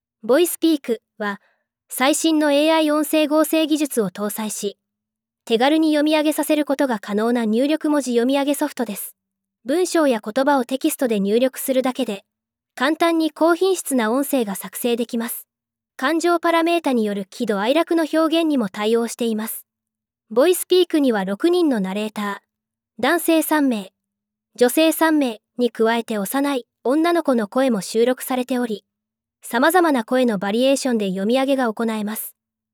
（こちらは上の文章を『VOICEPEAK』で読ませてみました。）